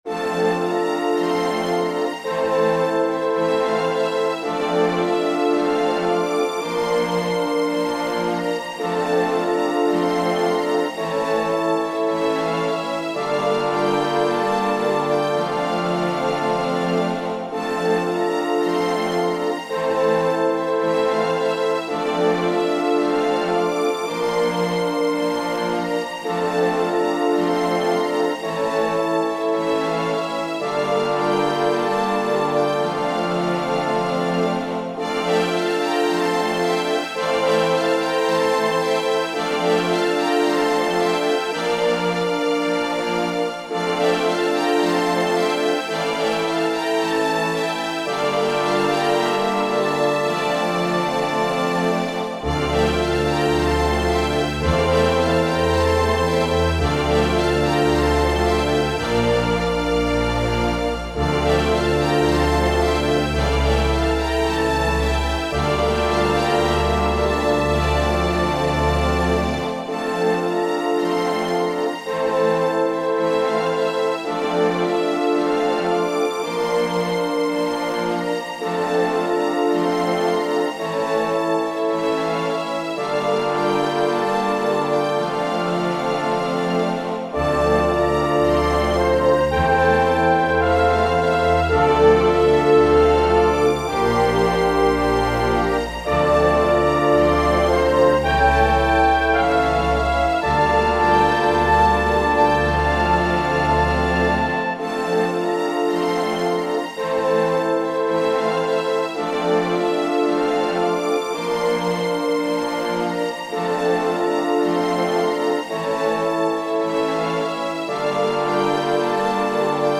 Theater Music